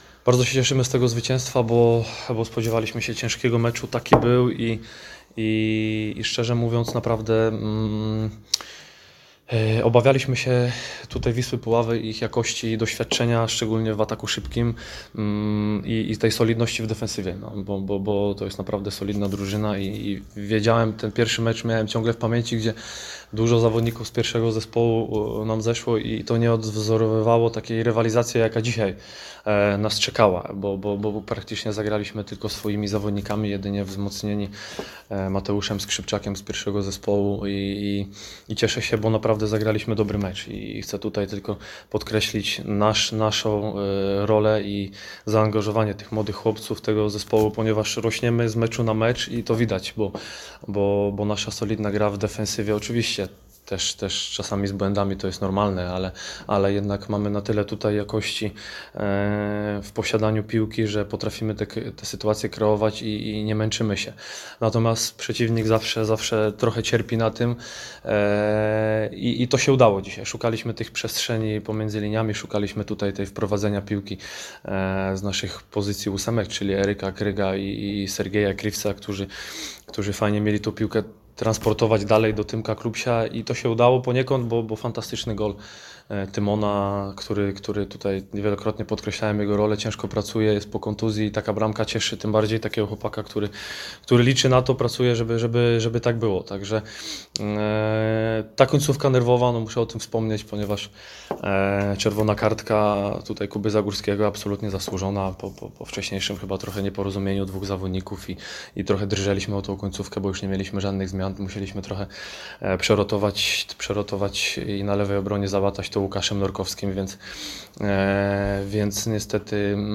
Konferencja prasowa po meczu Wisła Puławy – Lech II Poznań.